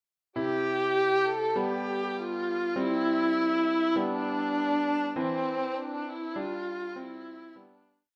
deutsches Weihnachtslied